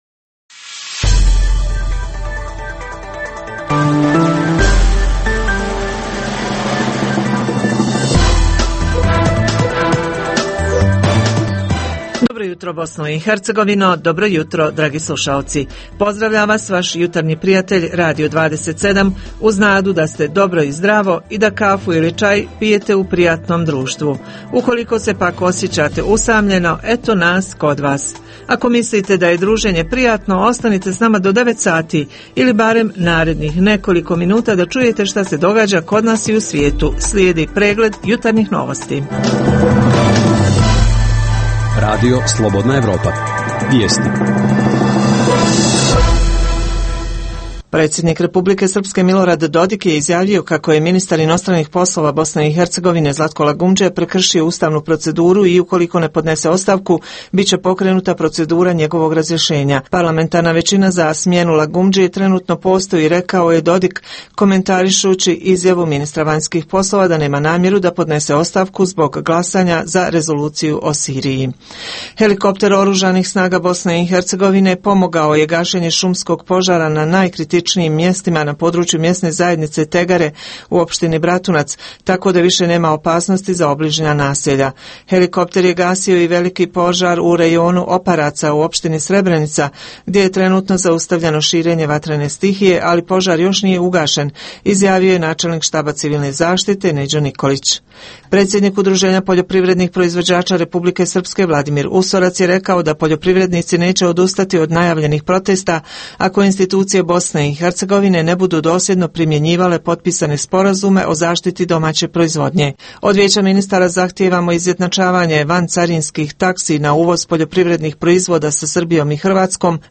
- Uz tri emisije vijesti, slušaoci mogu uživati i u ugodnoj muzici.